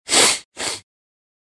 Шмыгают носом
Тут вы можете прослушать онлайн и скачать бесплатно аудио запись из категории «Анатомия, тело человека».